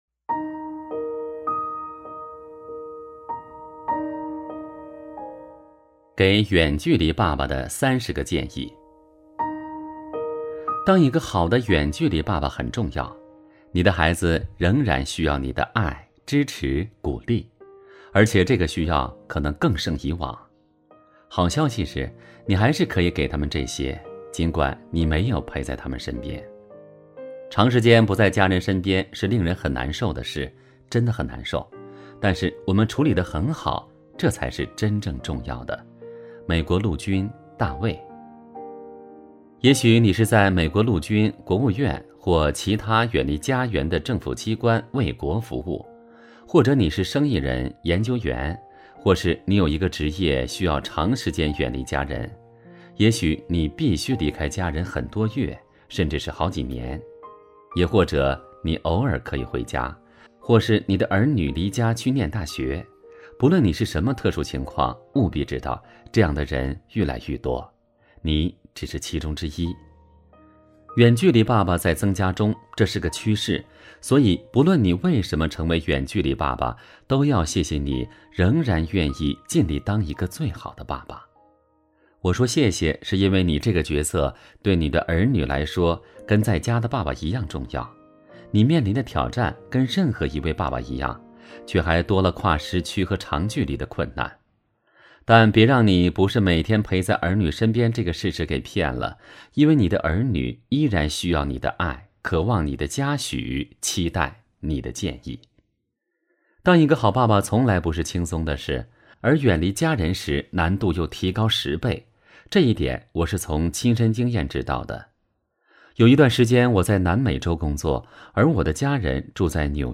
首页 > 有声书 > 婚姻家庭 | 成就好爸爸 | 有声书 > 成就好爸爸：39 给远距离爸爸的三十个建议